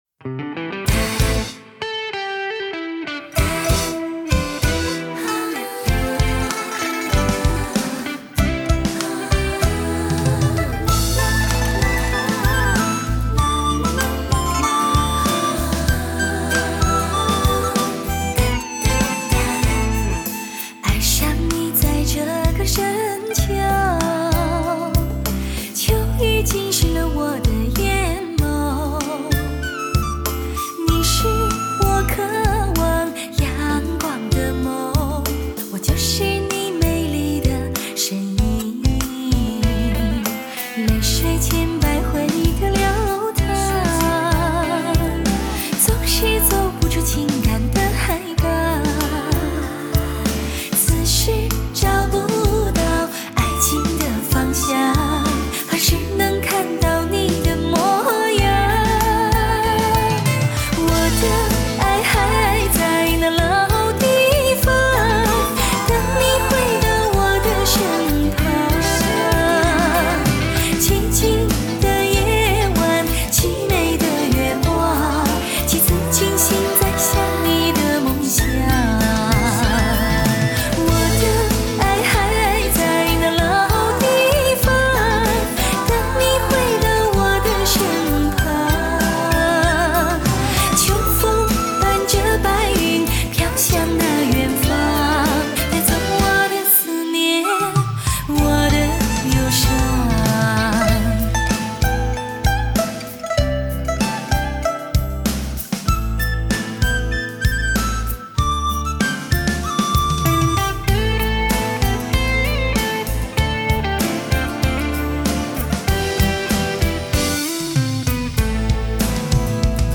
[甜美音色纯净声线]